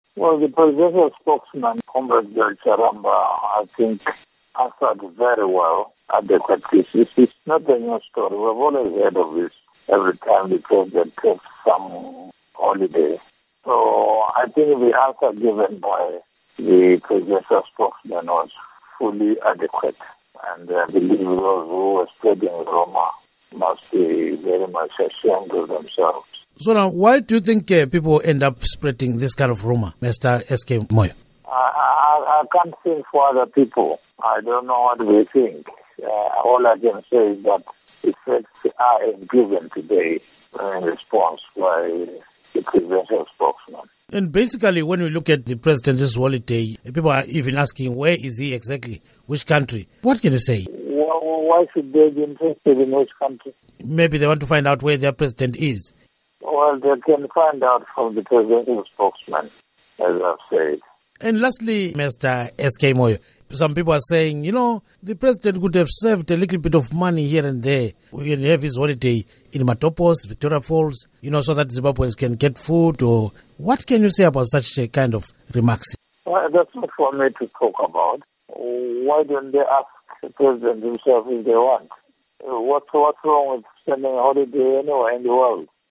Interview With Simon Khaya Moyo on Mugabe Health